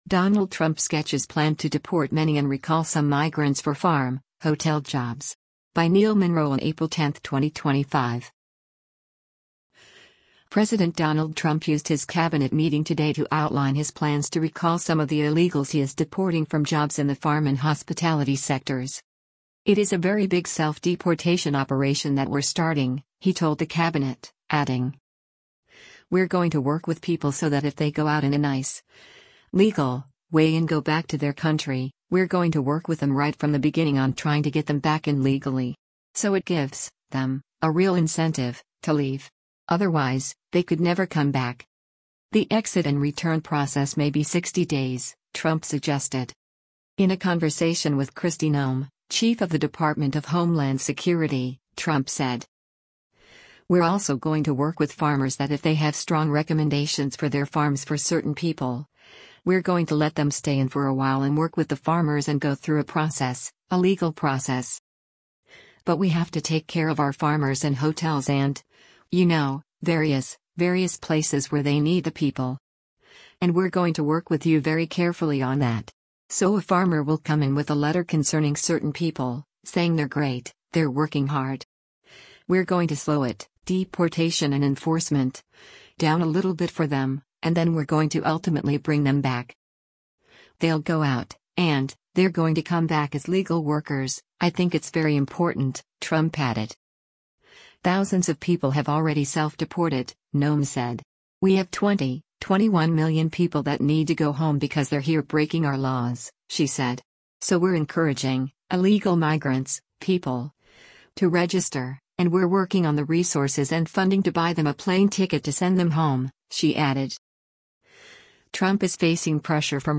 President Donald Trump used his cabinet meeting today to outline his plans to recall some of the illegals he is deporting from jobs in the farm and hospitality sectors.
In a conversation with Kristi Noem, chief of the Department of Homeland Security, Trump said: